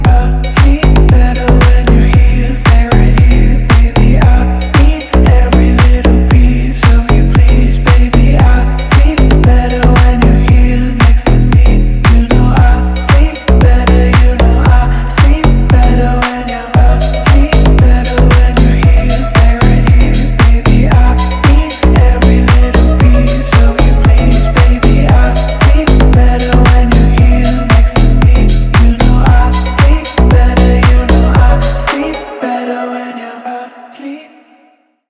красивые , Громкие рингтоны